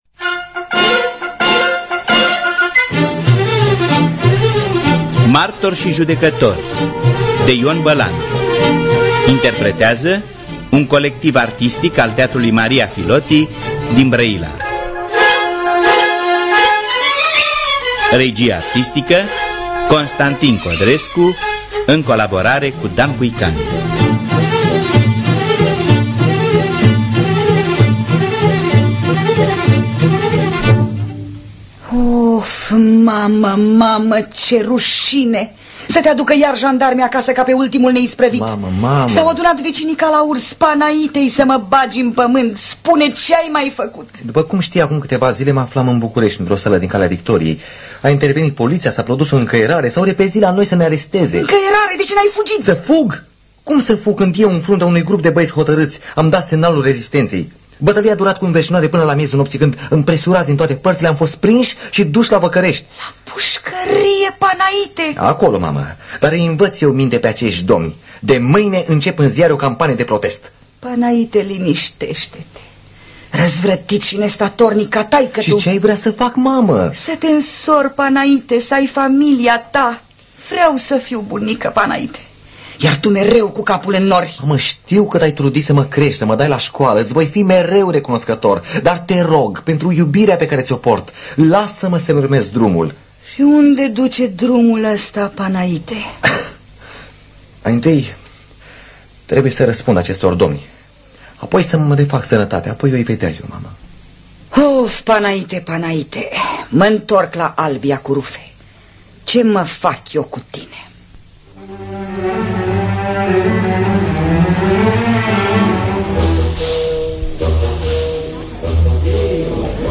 Martor și judecător de Ion Bălan – Teatru Radiofonic Online